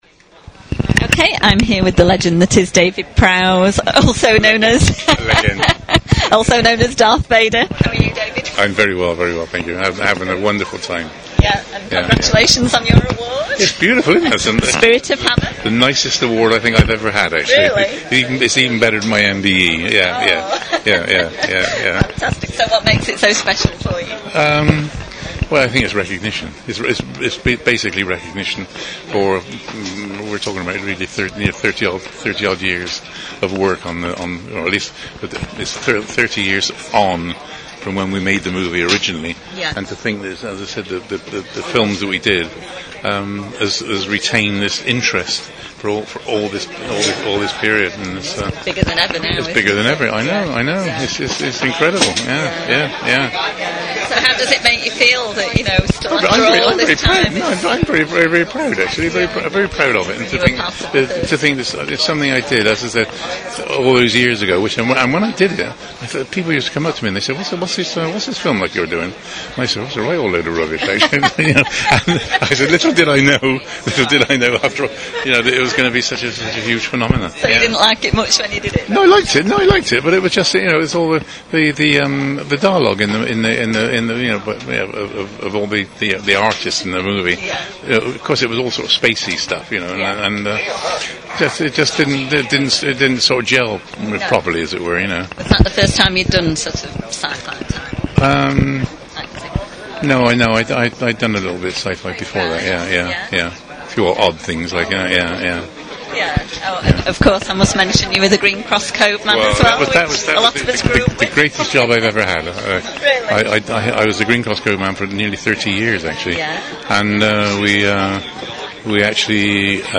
Posted in: Interviews.
dave-prowse-interview-2014.mp3